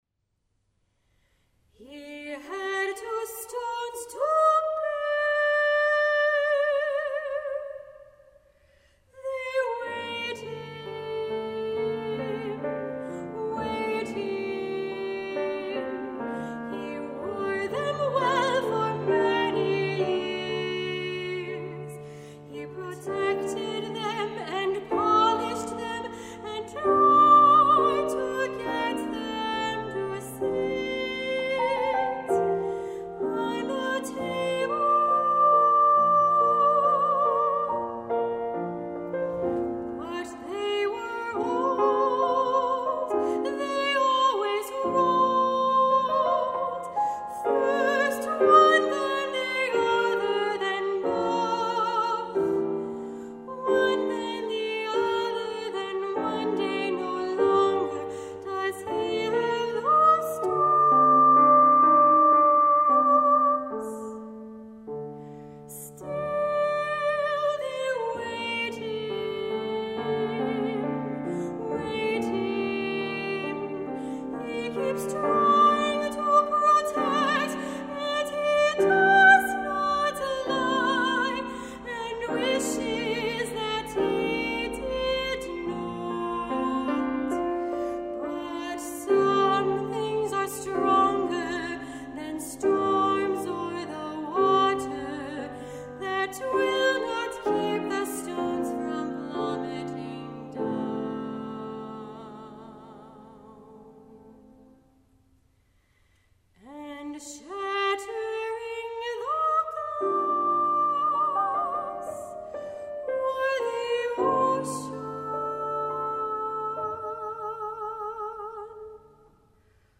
Soprano
Pianist